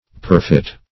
perfit - definition of perfit - synonyms, pronunciation, spelling from Free Dictionary Search Result for " perfit" : The Collaborative International Dictionary of English v.0.48: Perfit \Per"fit\ (p[~e]r"f[i^]t), a. Perfect.